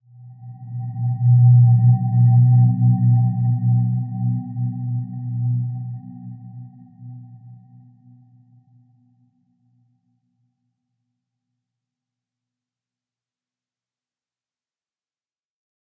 Dreamy-Fifths-B2-f.wav